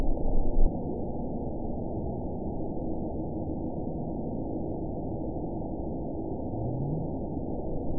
event 921127 date 04/29/24 time 00:12:55 GMT (1 year, 1 month ago) score 9.60 location TSS-AB08 detected by nrw target species NRW annotations +NRW Spectrogram: Frequency (kHz) vs. Time (s) audio not available .wav